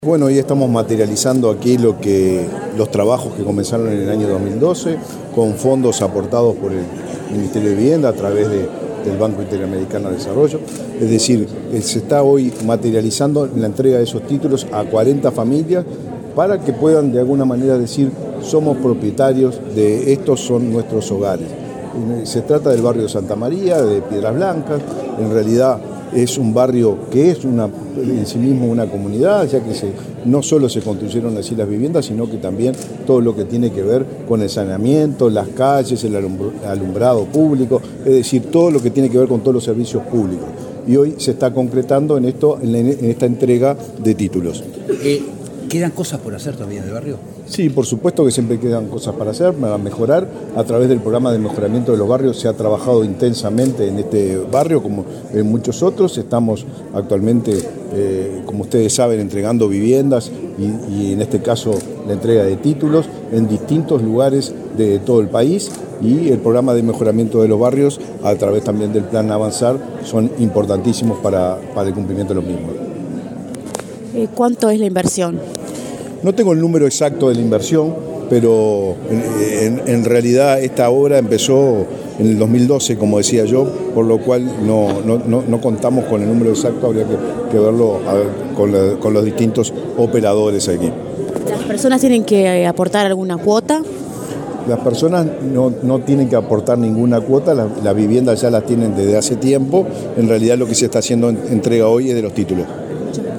Declaraciones del ministro de Vivienda, Raúl Lozano
El ministro de Vivienda, Raúl Lozano, dialogó con la prensa, luego de participar, en Montevideo, en el acto de firma de escrituras de 40 viviendas del